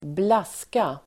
Ladda ner uttalet
Uttal: [²bl'as:ka]
blaska.mp3